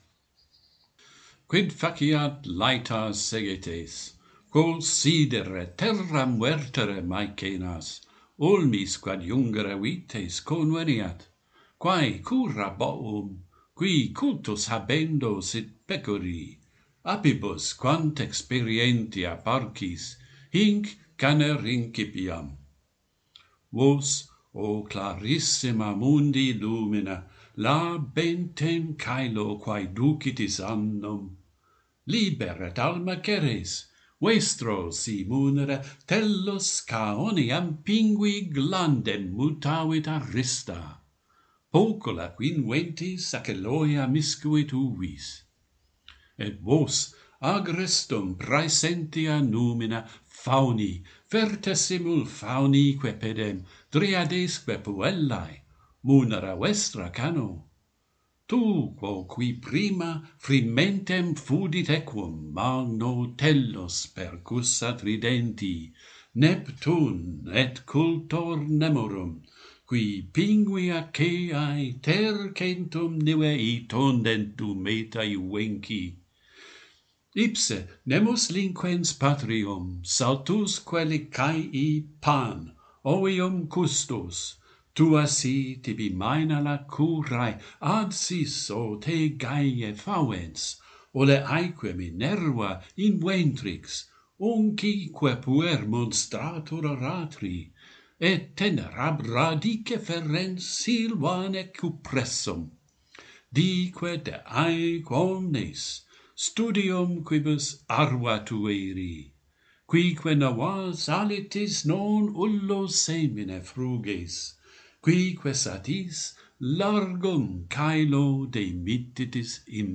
Virgil begins the Georgics - Pantheon Poets | Latin Poetry Recited and Translated